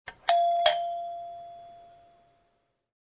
Звуки дверного звонка
Звон дверного звонка напоминающий чоканье бокалов